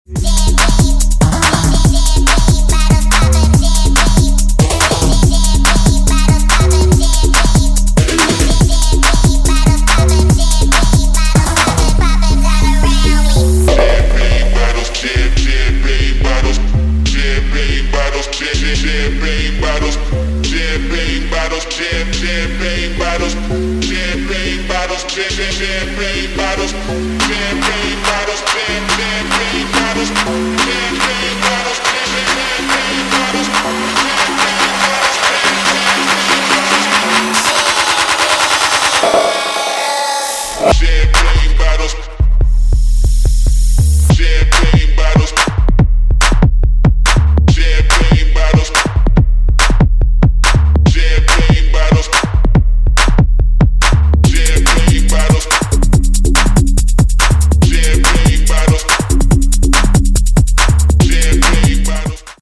Electro Bass